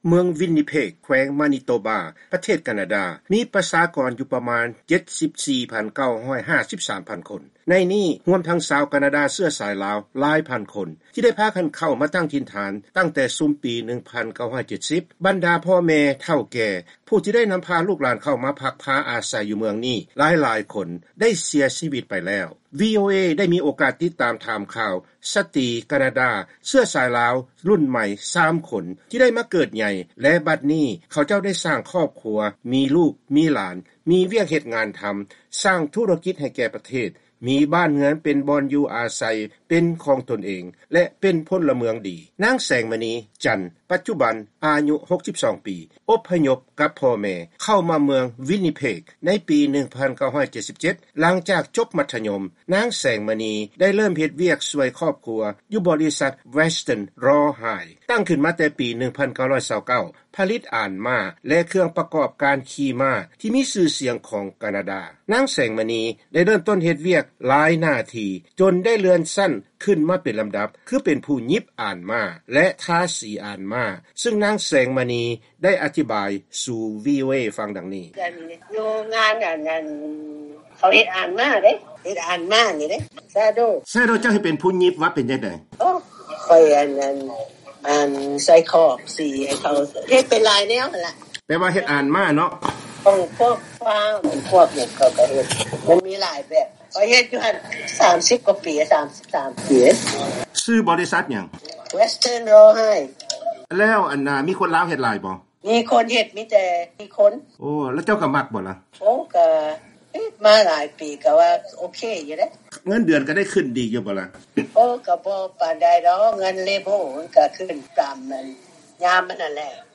ວີໂອເອລາວ ໂອ້ລົມກັບສາມສະຕີການາດາເຊື້ອສາຍລາວ ໃນເມືອງວິນນິເພກ ແຂວງມານິໂຕບາ ປະເທດການາດາ